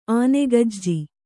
♪ ānegajji